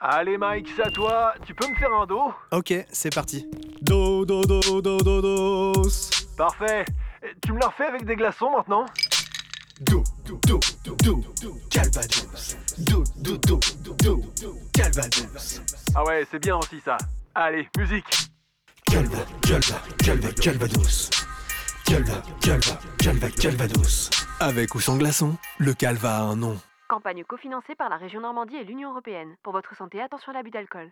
On vous laisse découvrir le spot.